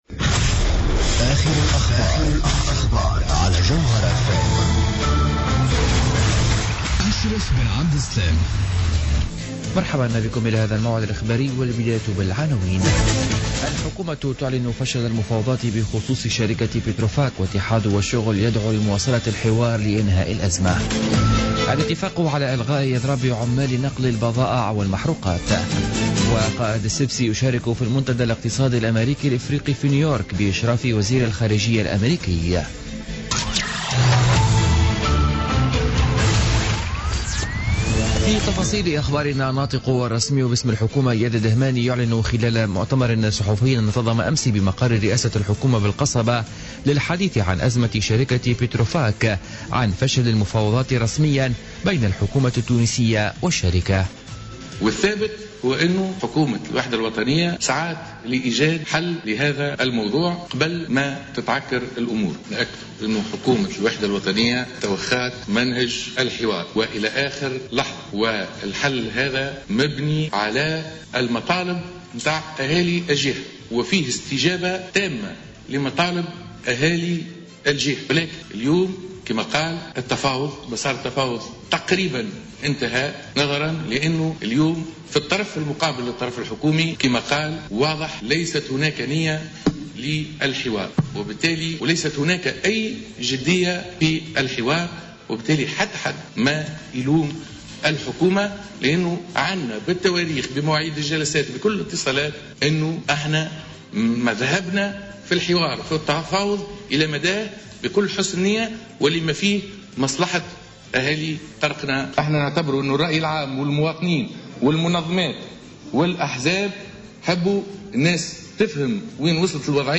Journal Info 00h00 du jeudi 22 septembre 2016